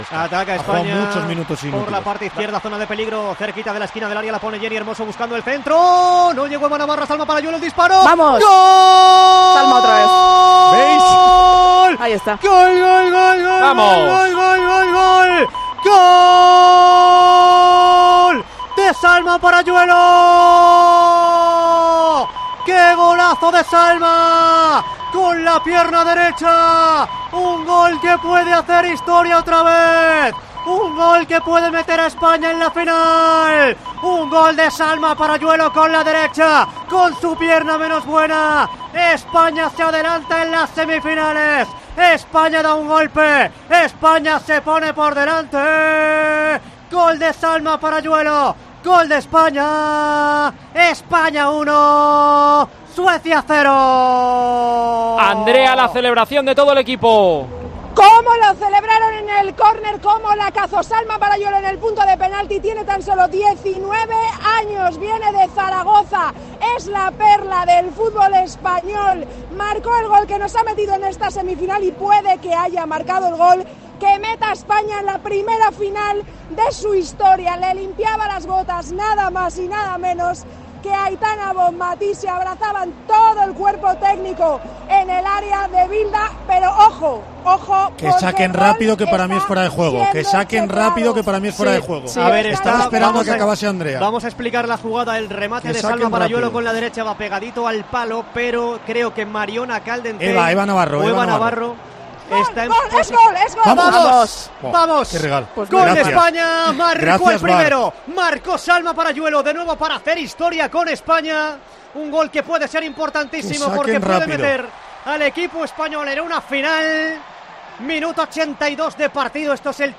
Revive la retransmisión del España-Suecia en Tiempo de Juego